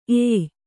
♪ ēy